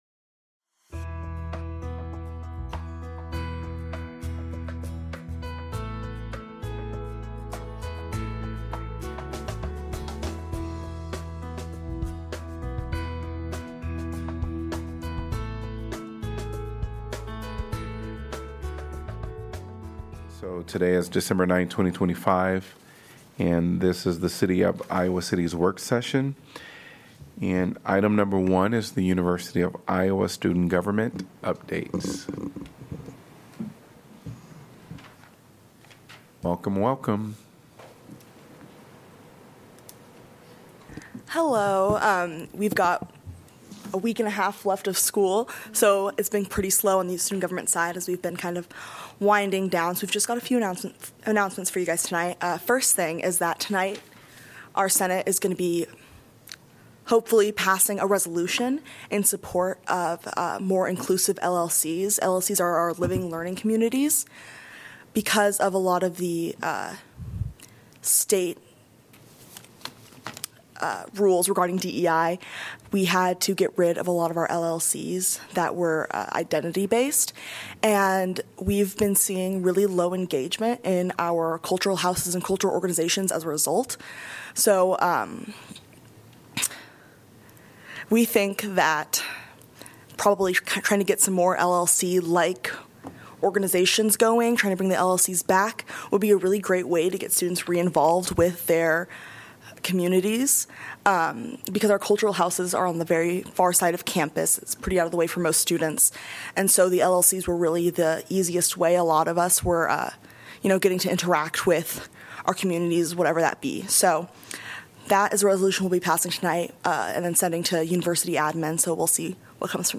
Audio podcast of the Iowa City Council meetings from the City's Cable TV Division.